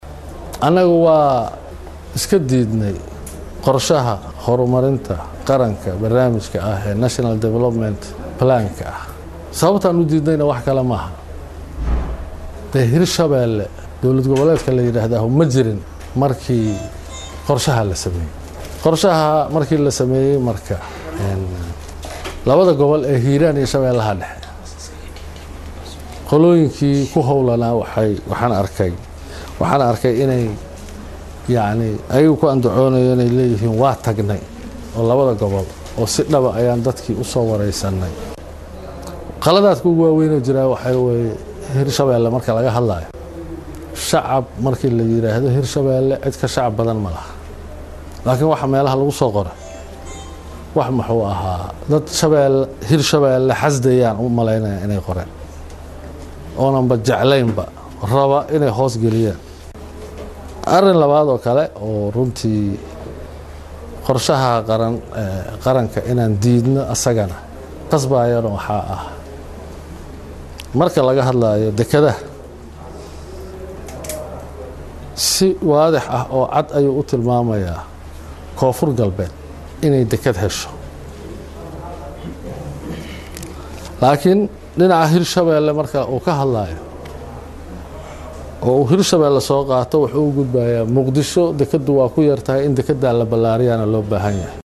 DHAGAYSO CODKA: Madaxweynaha Maamulka Hirshabeelle oo sheegay inay diideen Qorshaha Horumarinta Qaranka | Goobsan Media Inc